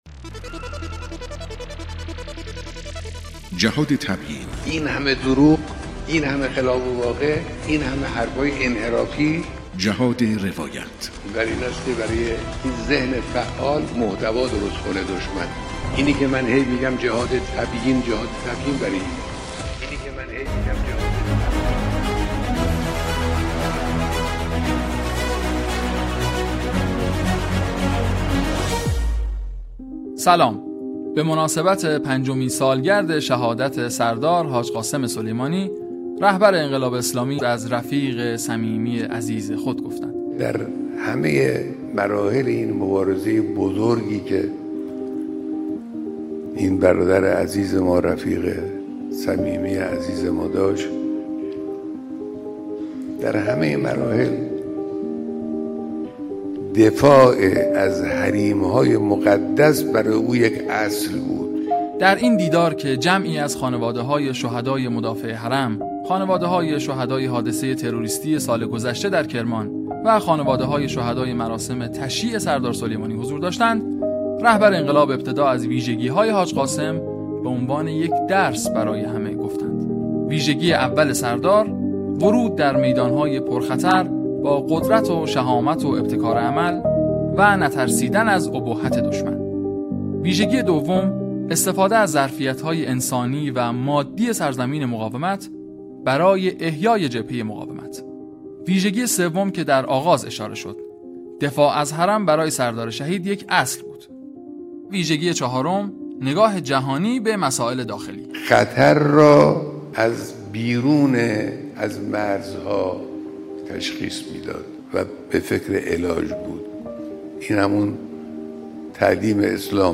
رادیو صدای انقلاب 1572 | جهاد تبیین : بیانات صبح چهارشنبه رهبر انقلاب